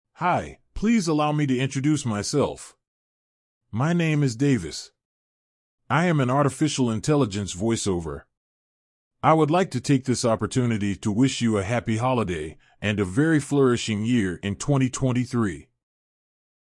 voiceover
Voiceover - Male